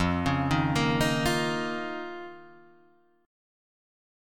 F7sus4#5 chord